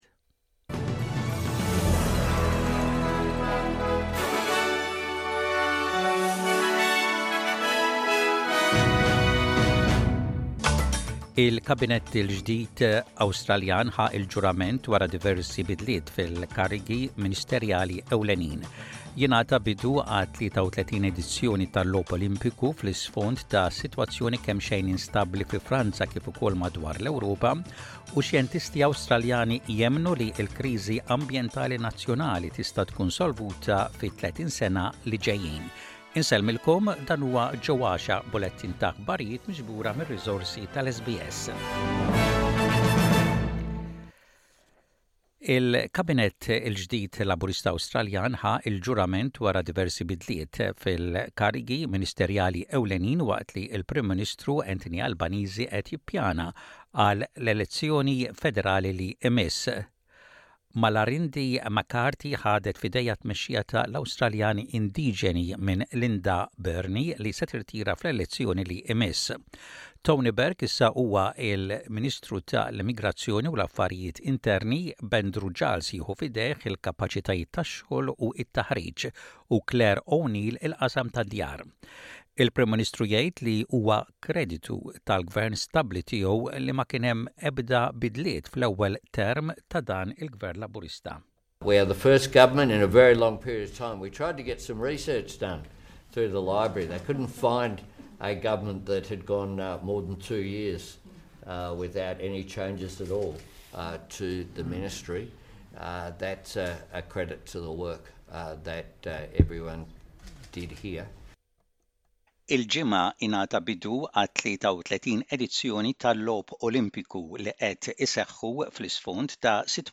SBS Radio | Aħbarijiet bil-Malti: 30.07.24